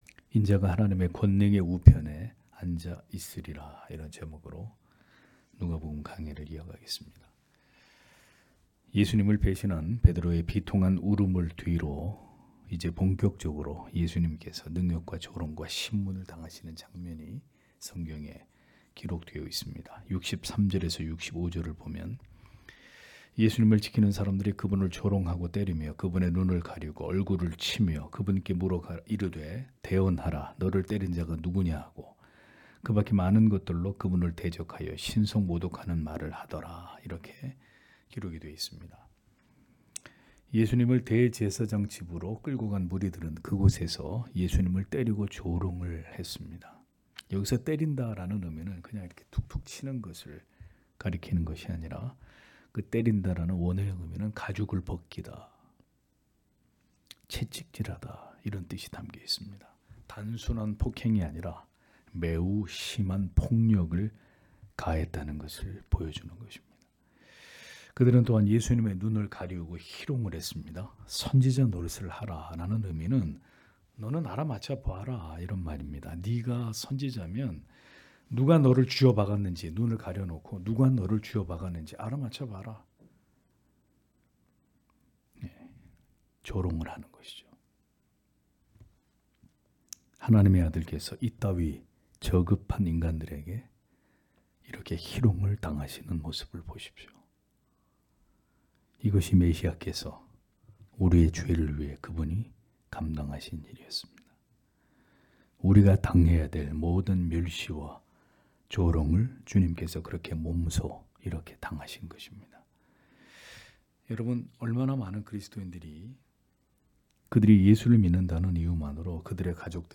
금요기도회 - [누가복음 강해 174] '인자가 하나님의 권능의 우편에 앉아 있으리라' (눅 22장 63- 71절)